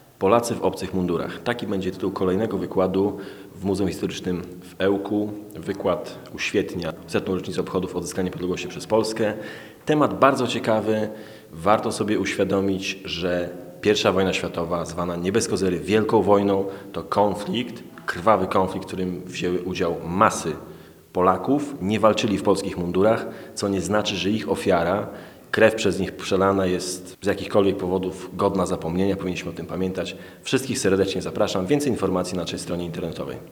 Kolejny wykład w muzeum
wykład-2.mp3